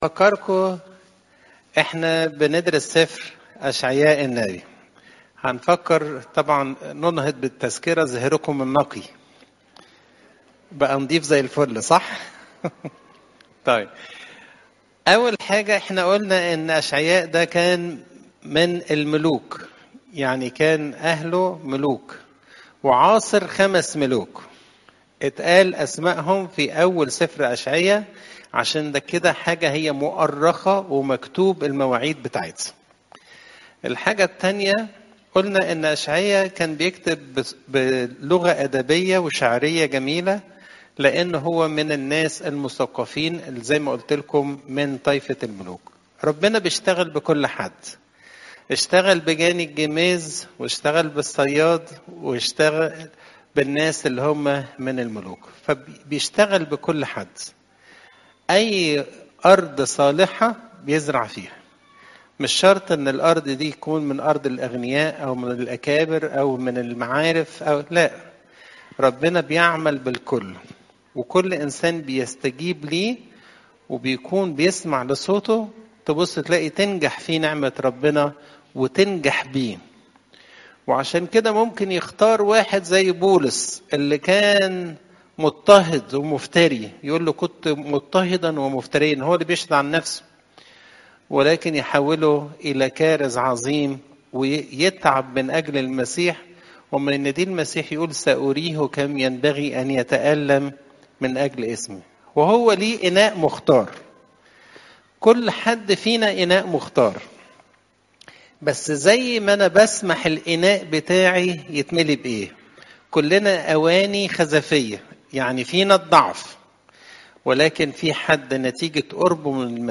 تفاصيل العظة